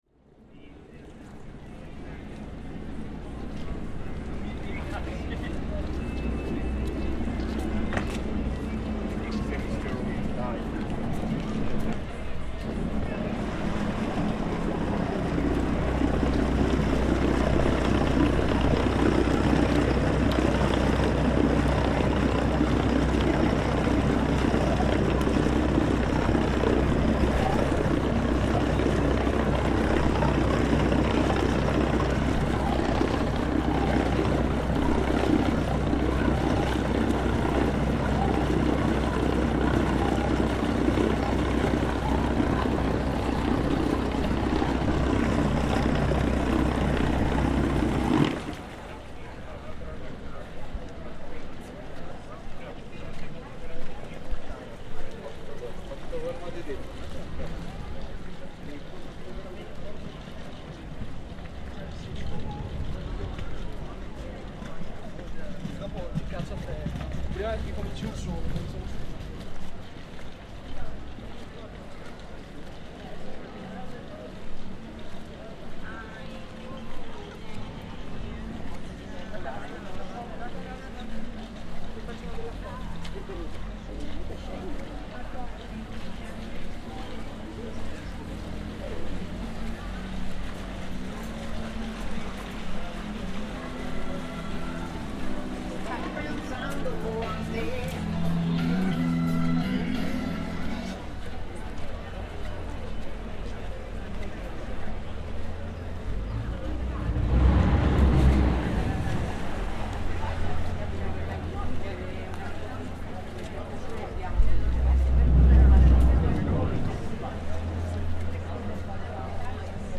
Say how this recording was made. It's aperitivo time in Cannaregio, Venice - bars by the side of the canal are filling up, and as we head down Fondamenta Cannaregio, we capture the classic soundscape of early evening in Venice, as the city downs tools for the day and claims its own "me time".